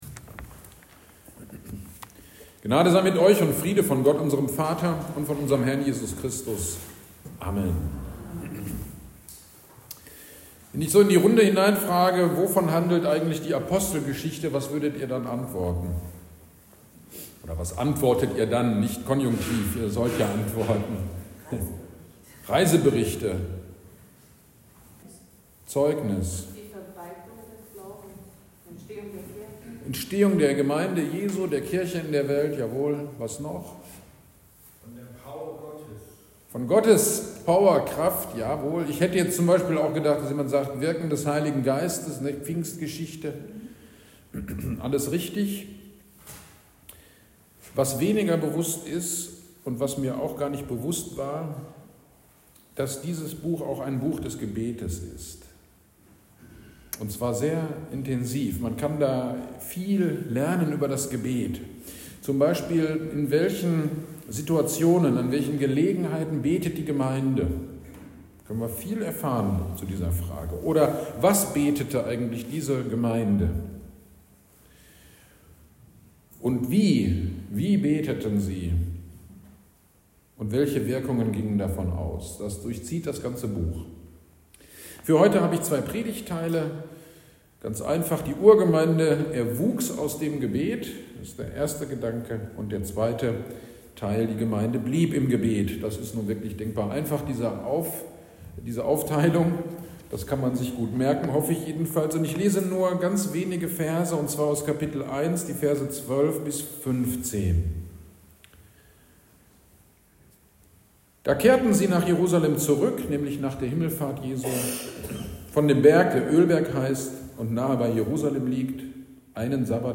GD am 25.05.25 Predigt zu Apostelgeschichte 1, 12-14